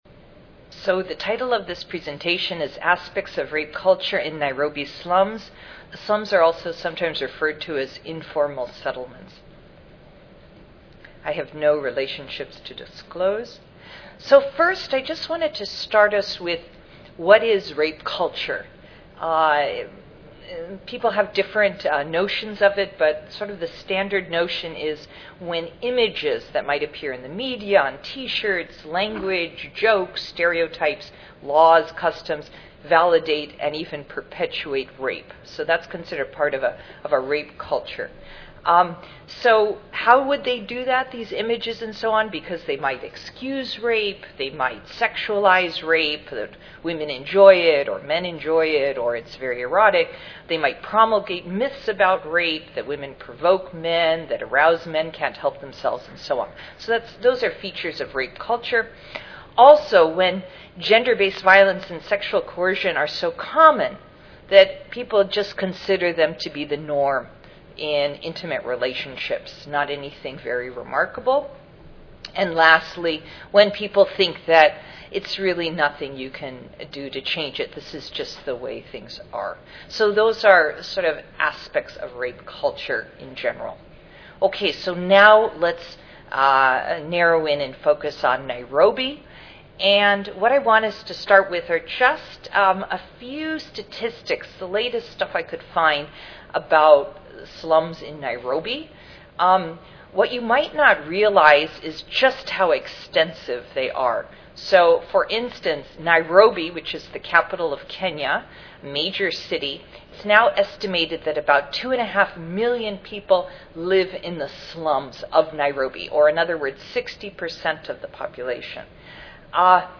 4255.0 Never no more: Cross-cultural examinations of intimate partner violence Tuesday, November 5, 2013: 12:30 p.m. - 2:00 p.m. Oral Intimate partner violence occurs across all cultures.